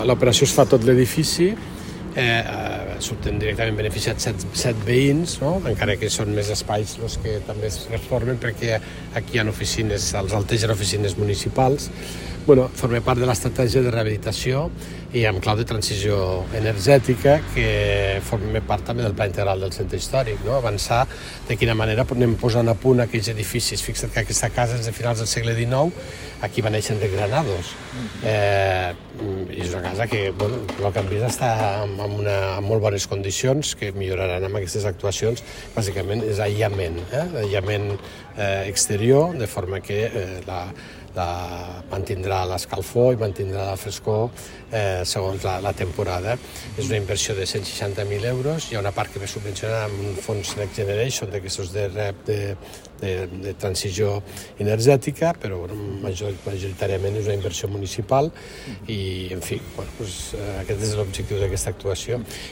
Tall de veu de l'alcalde, Fèlix Larrosa Es tracta d’un edifici de 8 habitatges de lloguer assequible.